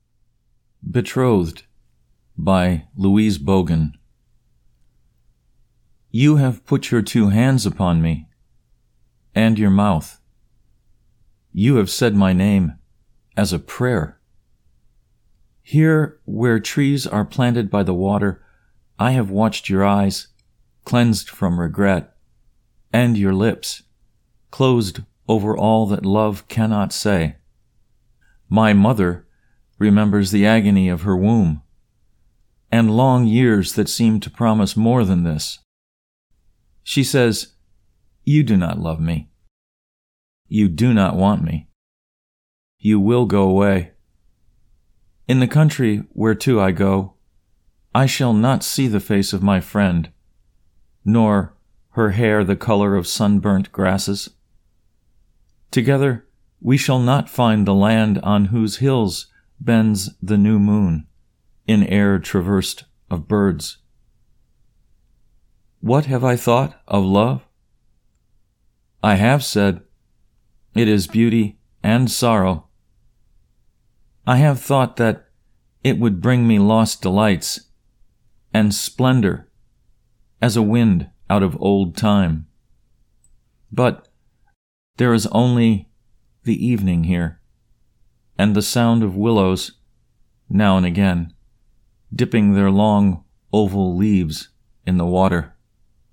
Betrothed © by Louise Bogan (Recitation)